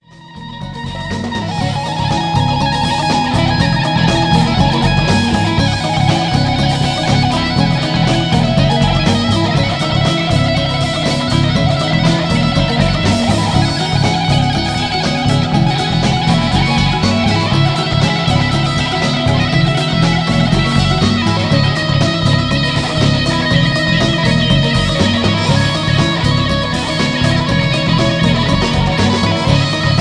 Electric Guitar instrumental single track.